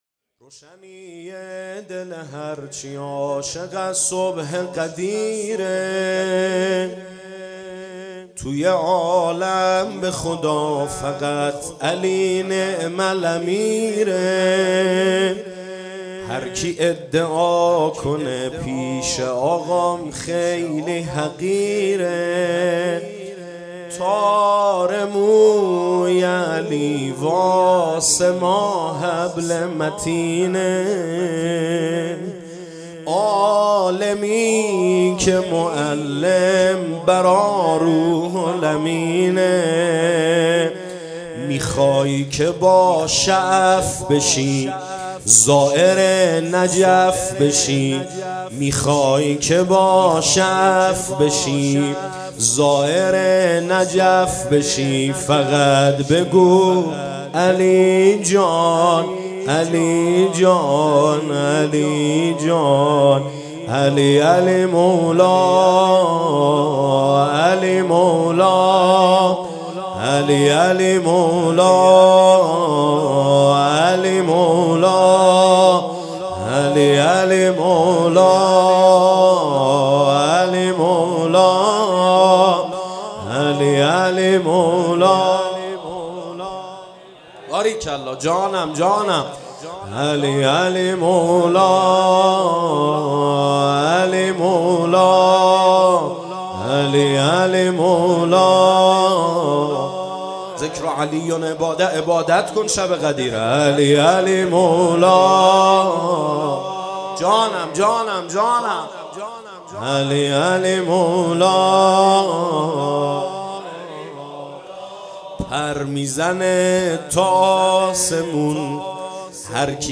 جشن عید غدیر خم | ۲۶ تیرماه ۱۴۰۱